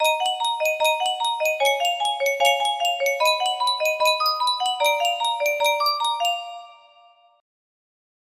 ts is fireeeeeeeeeeeeeeeeeeeeeeeeeeeeeeeee music box melody
Full range 60